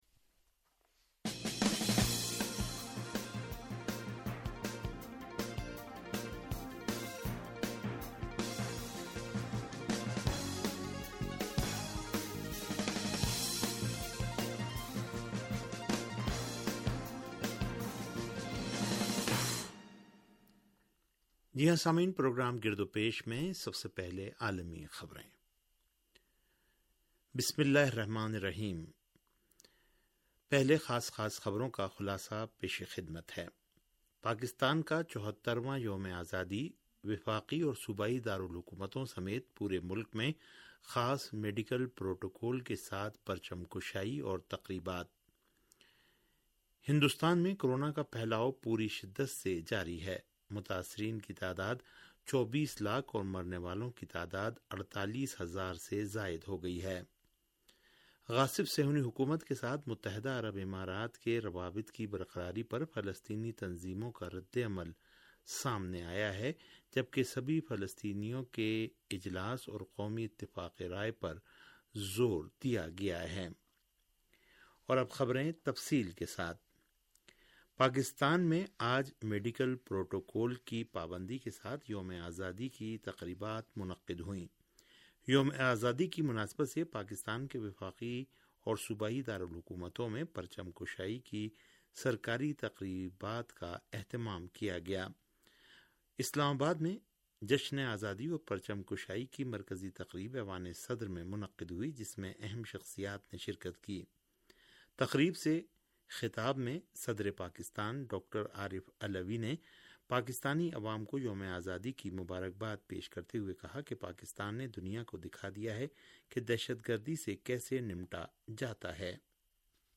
ریڈیو تہران کا سیاسی پروگرام - گرد و پیش